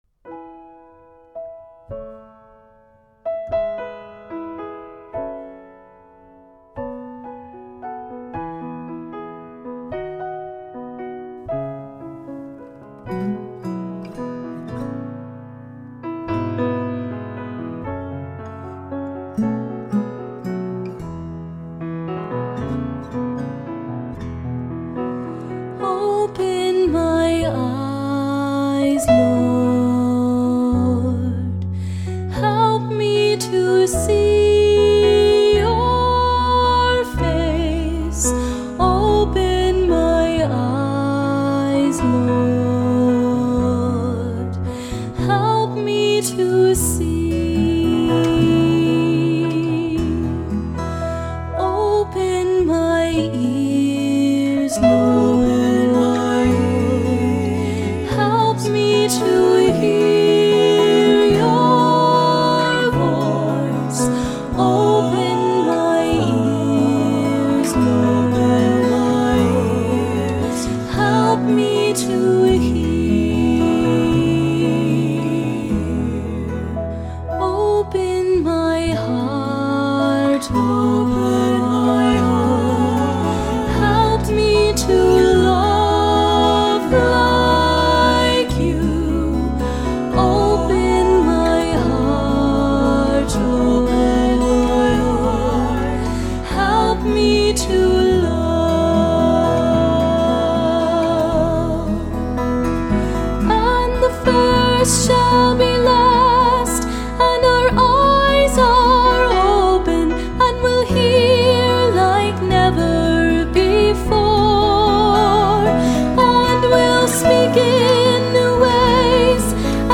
Concert Recordings for Download and Practice
(all parts )